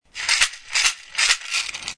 マラカス【アフリカ ラッスル 民族楽器】 (w135-06)
カメルーンで作られたマラカスです。
小さな木の実が入っています。
音はそれほど大きくありませんが独特の乾いたサウンドが魅力的です。
この楽器のサンプル音
木、木の実